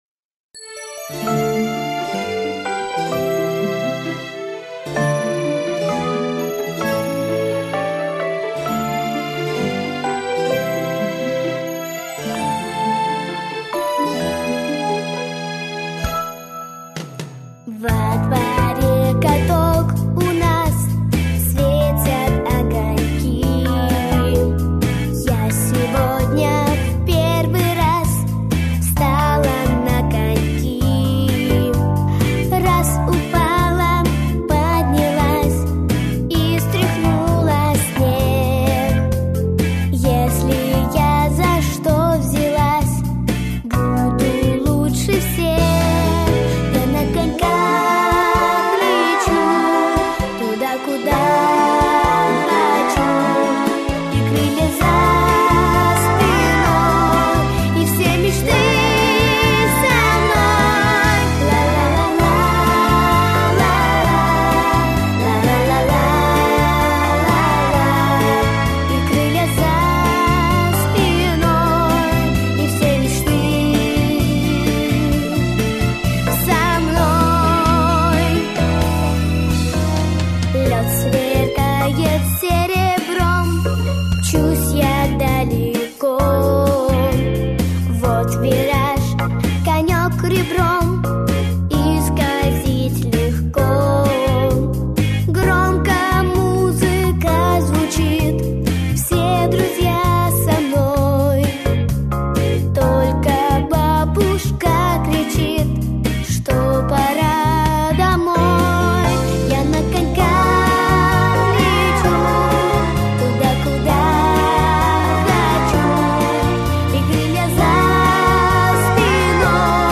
Аудиокнига Зимние виды спорта | Библиотека аудиокниг